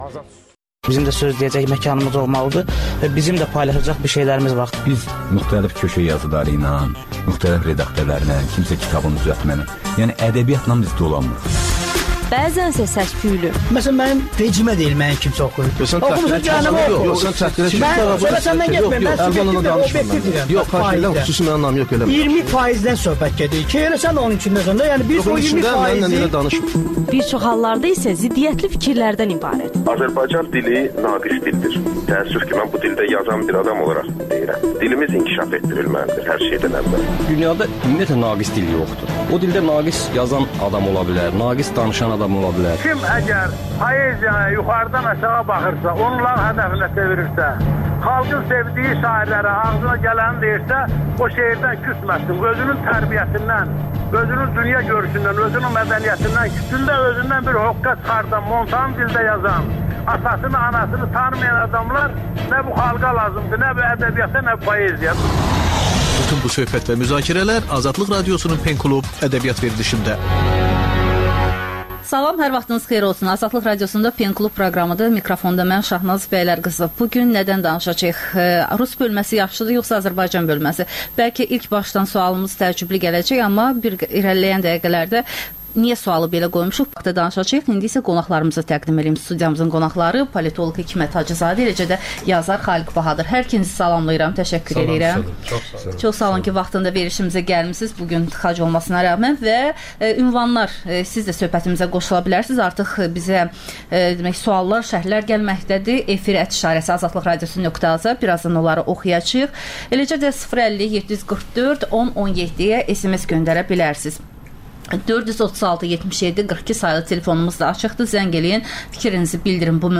Azərbaycanda və dünyda baş verən hadisələrin ətraflı analizi, təhlillər, müsahibələr.